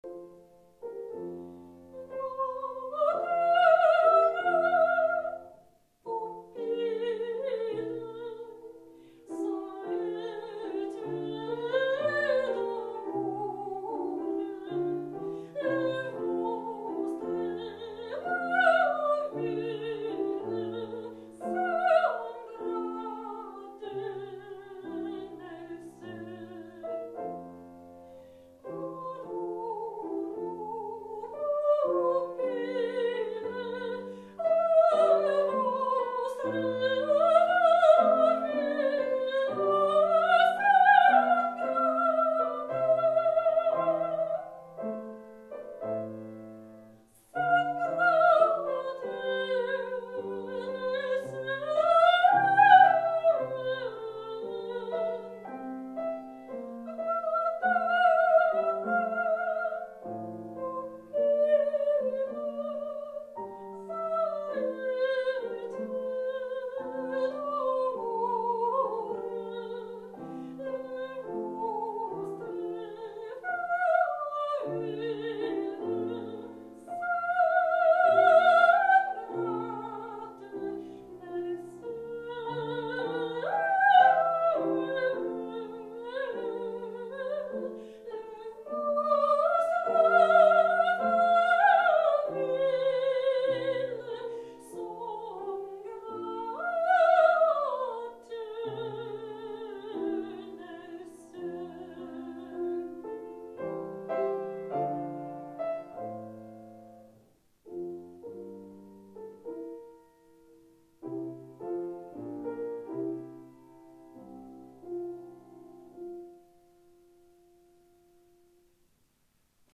Ich bin Sopranistin und interpretiere sehr gern Klassische Musik, angefangen von Liedern und Arien alter italienischer Meister und Werken von J.S.Bach, G.F.Händel, über Kompositionen von W.A.Mozart, J.Haydn, F.Schubert, J.Brahms, G. Puccini, G.Verdi bis hin zu G.Gershwin - um nur einige Beispiele zu nennen.